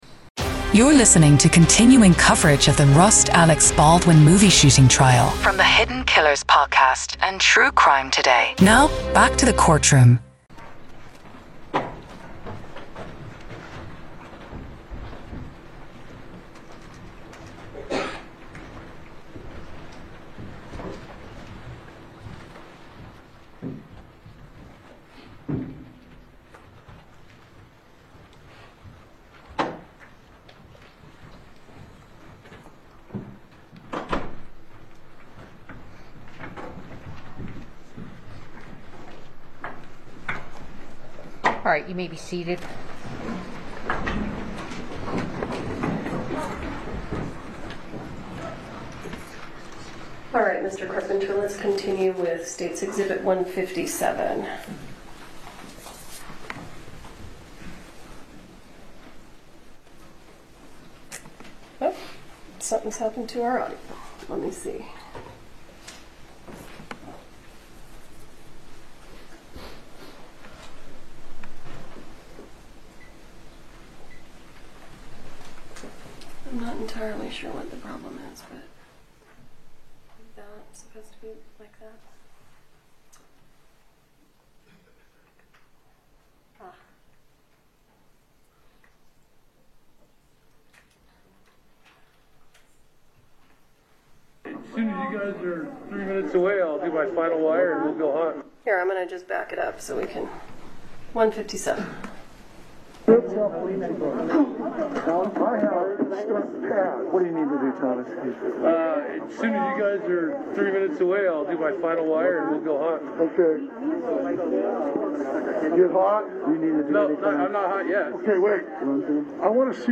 Go beyond the headlines: Hear the emotional pleas and heated arguments directly from the courtroom. Gain insider insights from legal experts as they break down the complex charges, the defense strategy, and the potential impact on the industry.